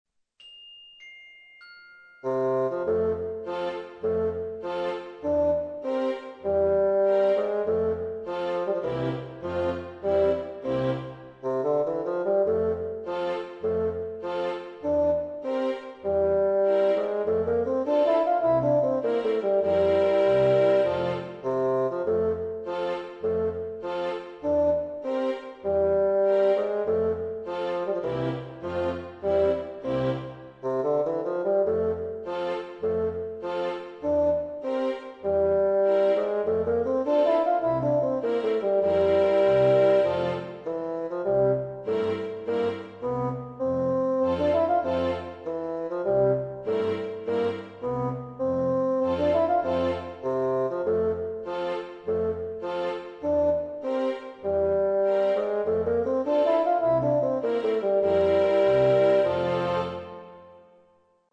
Proponiamo l'aria in versione didattica per flauto.